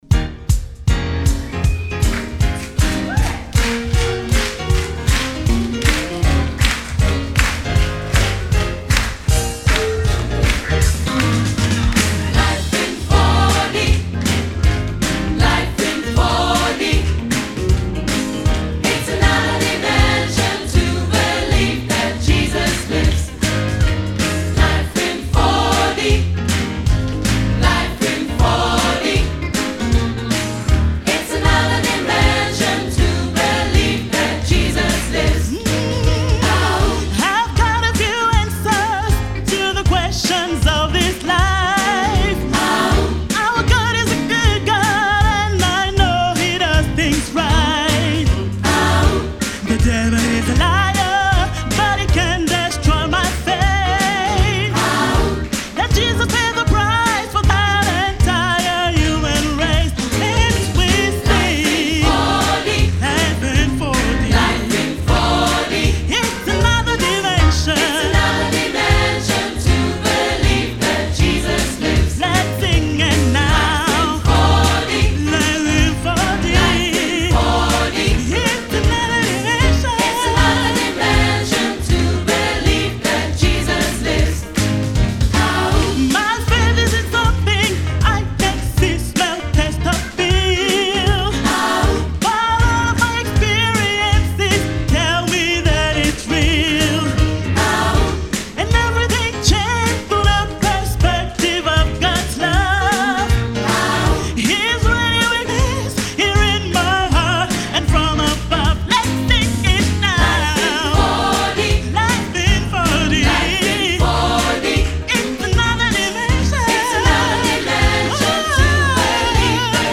• SAB, Solist + Piano